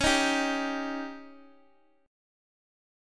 Dbsus2b5 chord